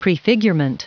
Prononciation du mot prefigurement en anglais (fichier audio)
Prononciation du mot : prefigurement